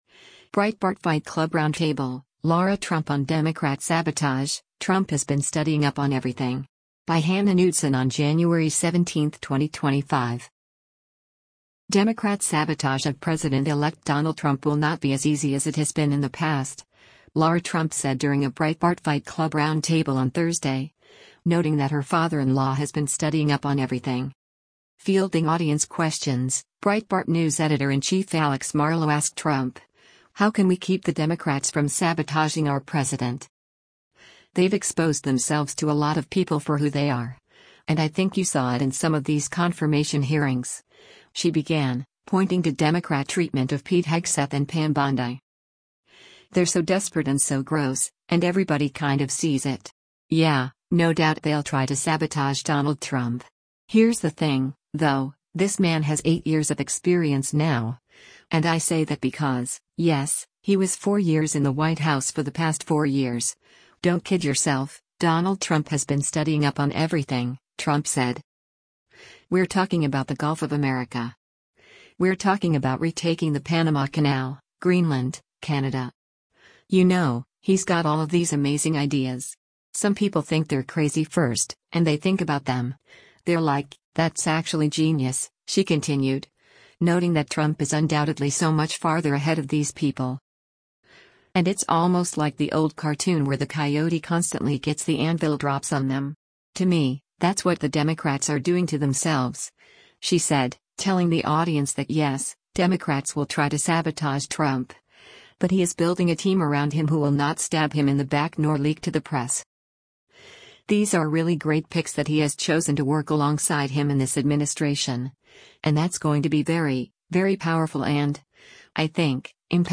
Democrat sabotage of President-elect Donald Trump will not be as easy as it has been in the past, Lara Trump said during a Breitbart Fight Club Roundtable on Thursday, noting that her father-in-law has been “studying up on everything.”